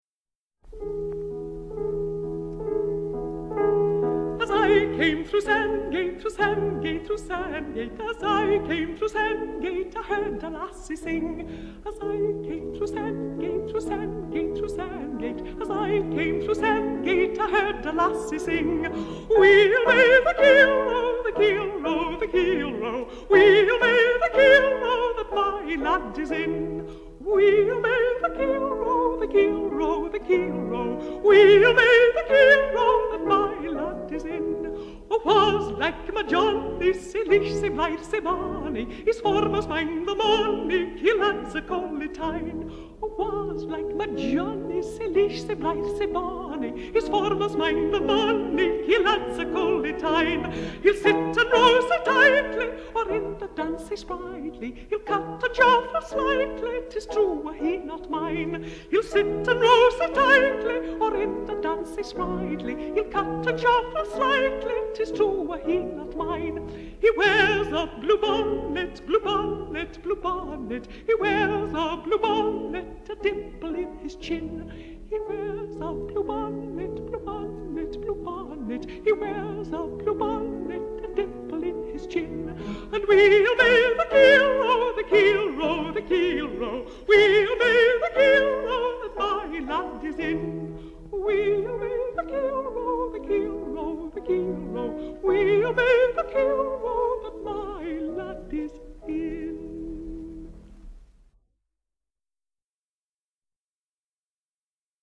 Highlights included Dr. Fowles’ “Arise, shine for thy light is come”, but the audience’s favorite was the secular song “Weel may the keel row”.
Kathleen-Ferrier-Keel-Row.m4a